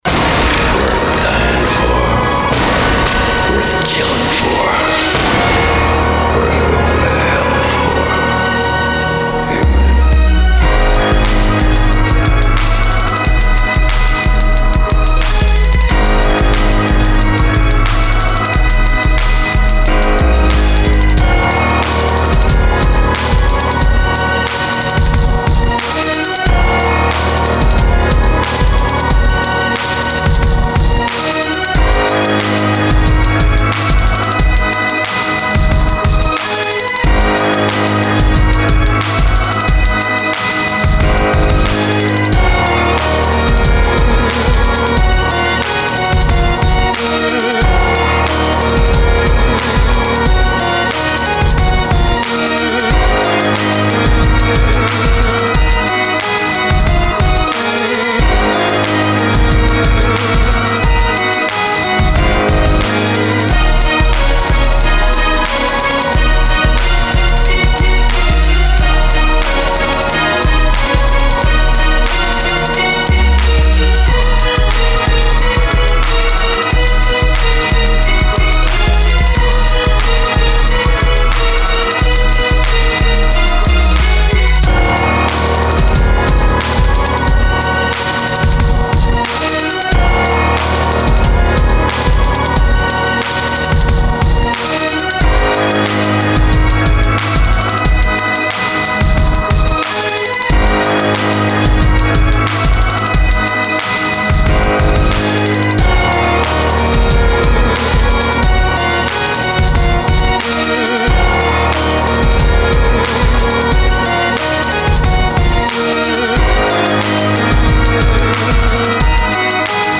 Beats